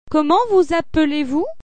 Comment vous appelez-vous ?   loak chh* mu-uhH uhvuh-ee